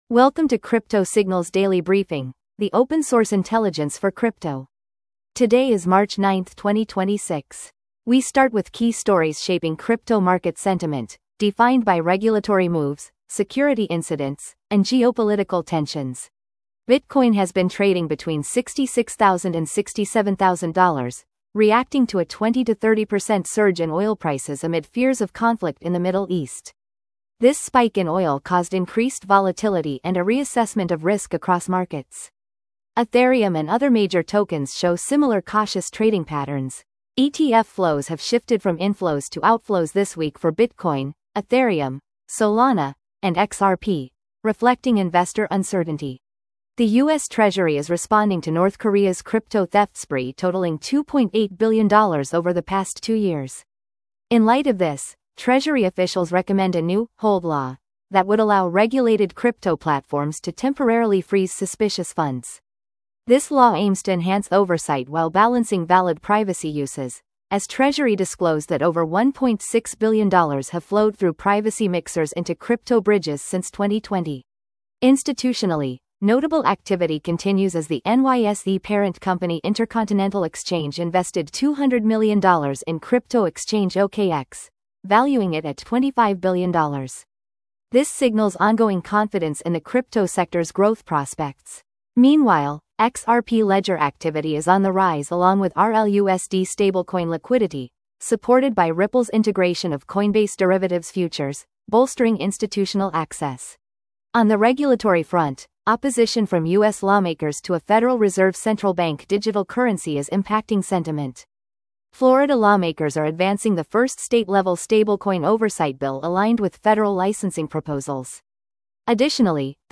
Crypto Signals Daily market brief